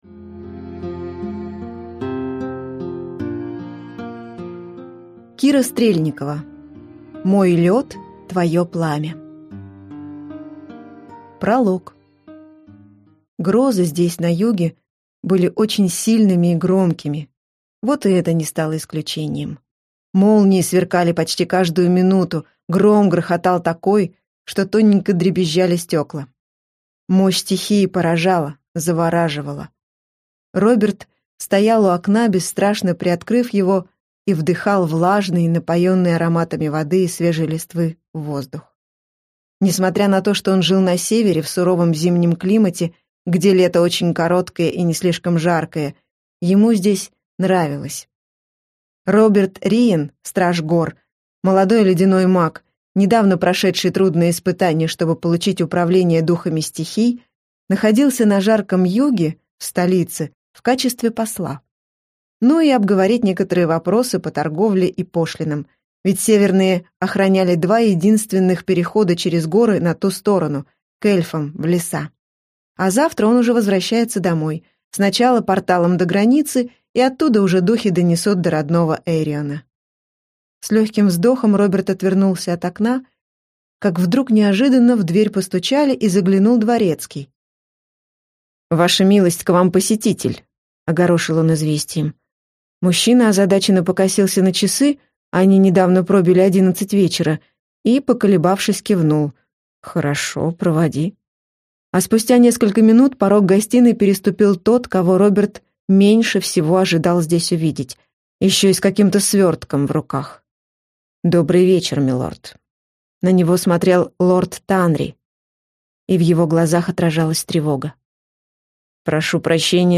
Аудиокнига Мой лёд, твоё пламя | Библиотека аудиокниг
Прослушать и бесплатно скачать фрагмент аудиокниги